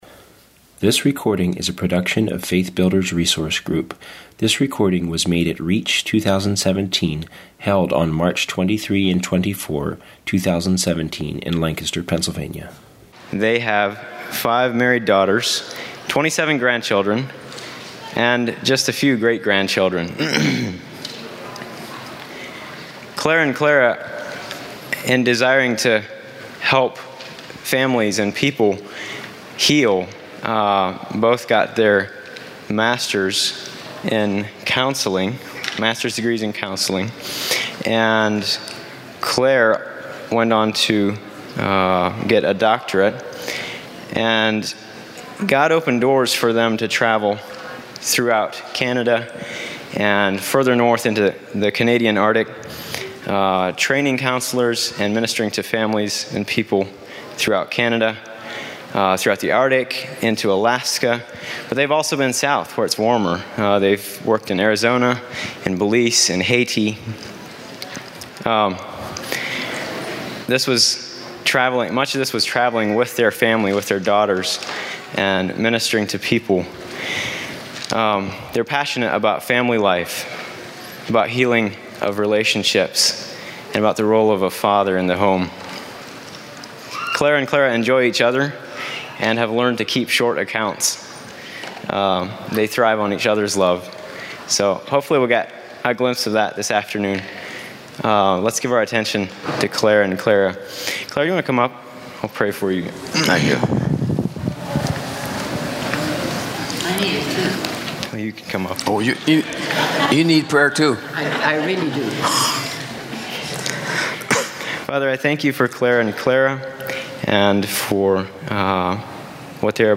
Home » Lectures » The Joy of Husband and Wife Relationships